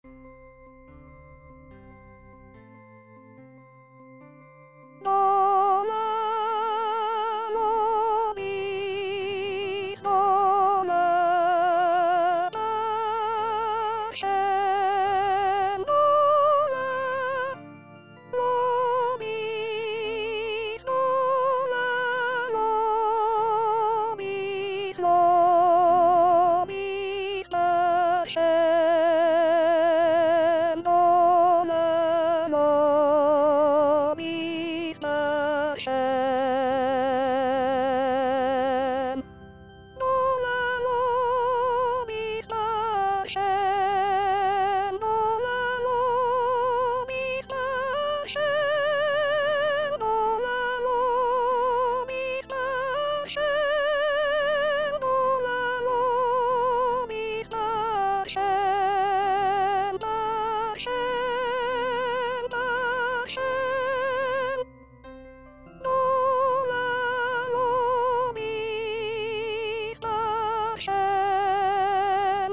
Chanté: